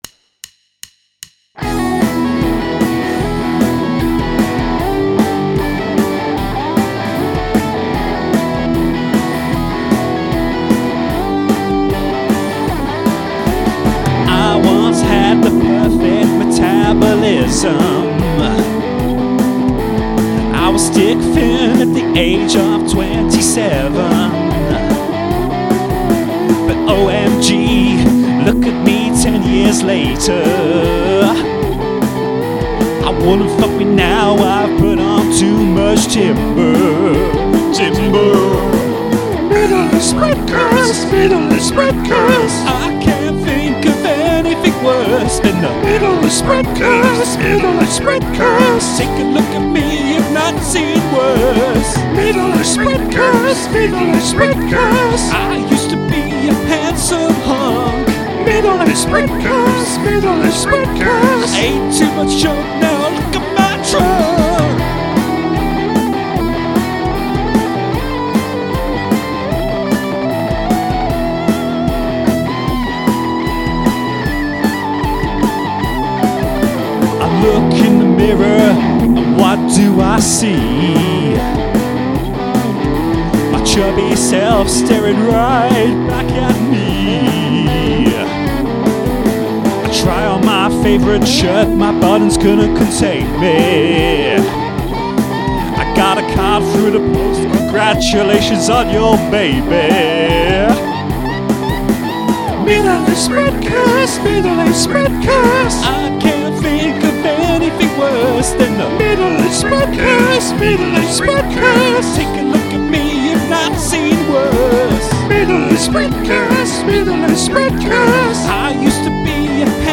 but I upped the pace, gave the guitars more punch